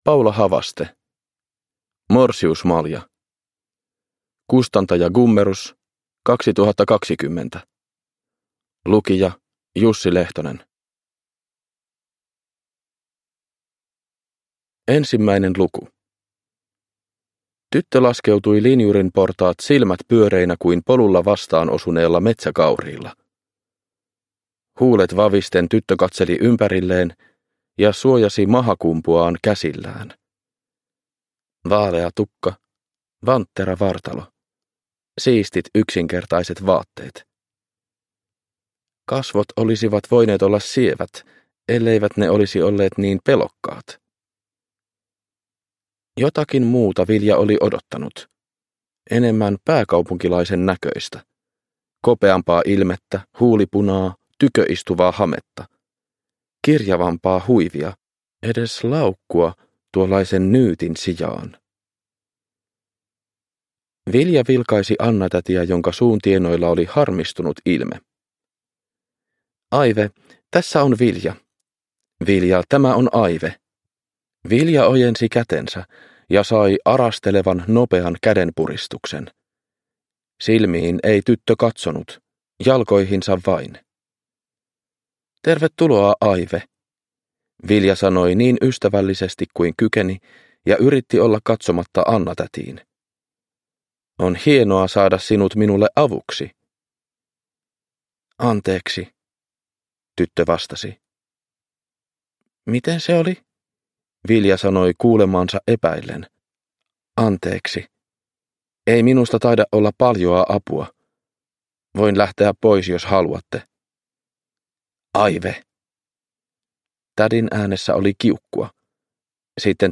Morsiusmalja – Ljudbok – Laddas ner
Produkttyp: Digitala böcker